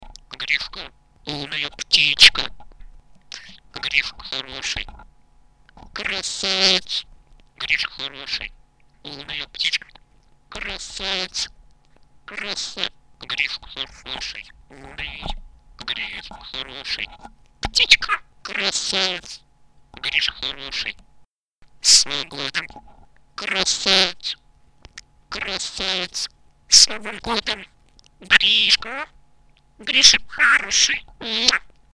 На этой странице собраны звуки, которые издают попугаи: от веселого чириканья до мелодичного пения.
Попугай освоил пару новых слов